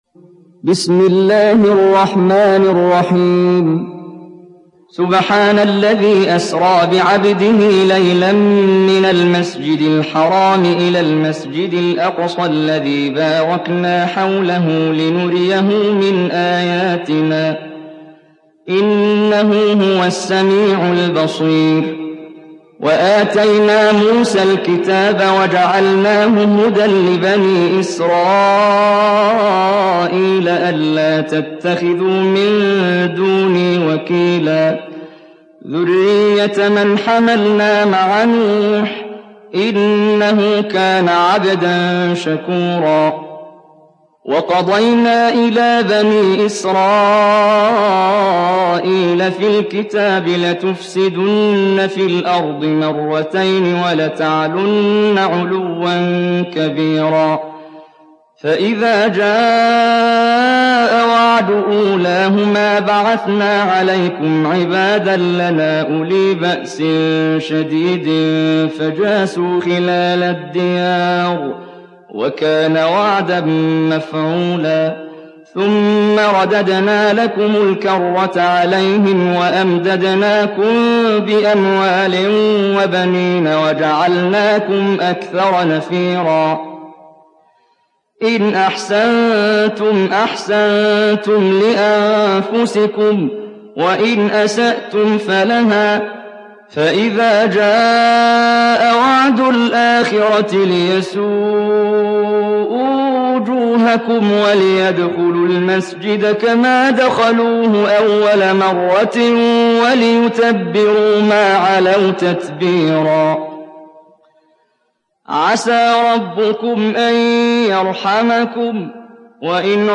Surat Al Isra Download mp3 Muhammad Jibreel Riwayat Hafs dari Asim, Download Quran dan mendengarkan mp3 tautan langsung penuh